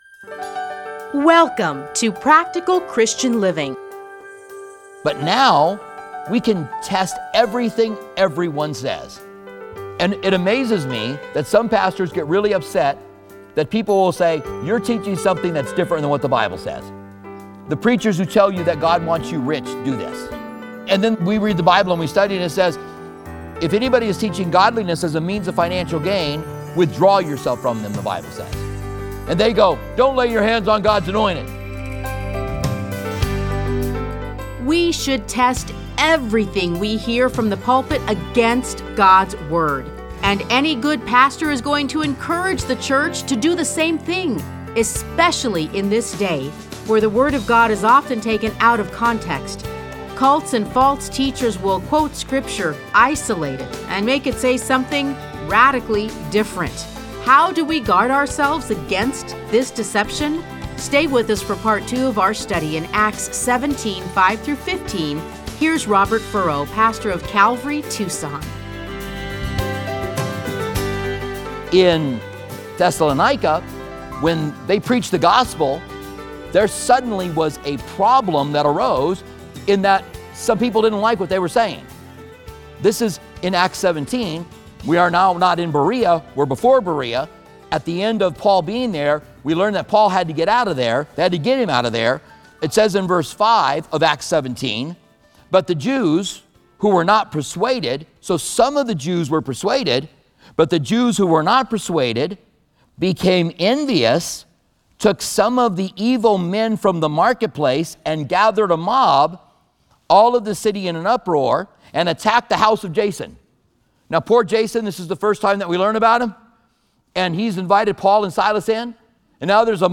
Listen to a teaching from Acts 17:5-15.